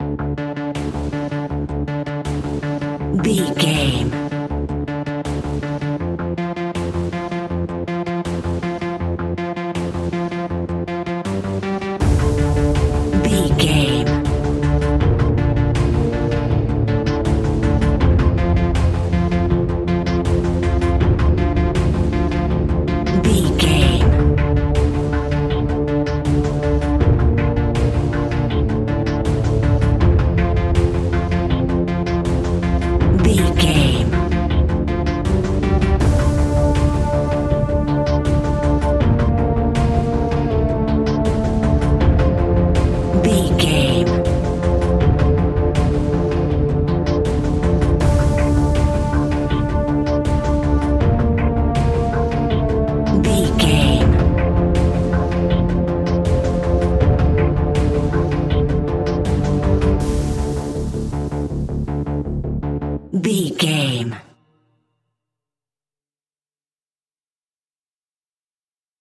Aeolian/Minor
D
ominous
dark
haunting
eerie
synthesiser
strings
drums
percussion
instrumentals
horror music